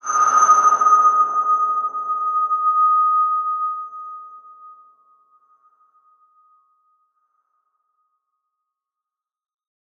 X_BasicBells-D#4-ff.wav